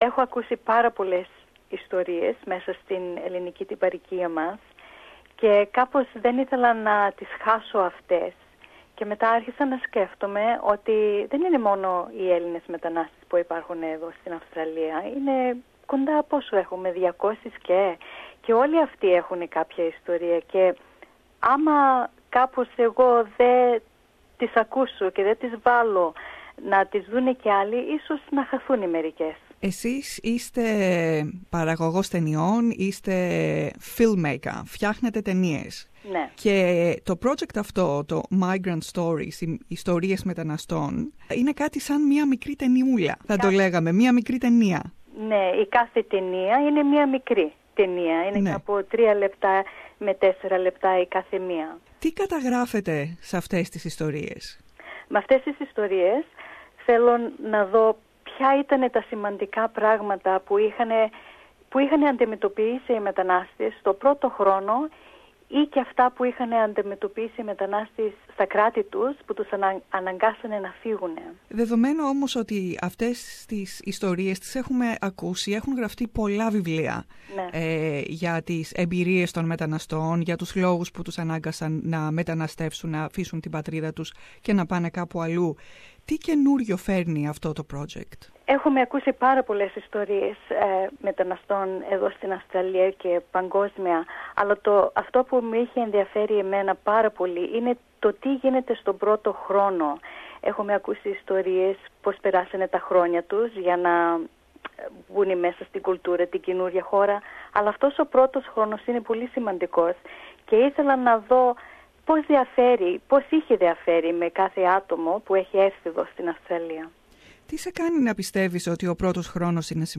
Περισσότερα ακούμε στην συνομιλία